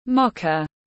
Cà phê mô-cha tiếng anh gọi là mocha, phiên âm tiếng anh đọc là /ˈmɒk.ə/
Mocha /ˈmɒk.ə/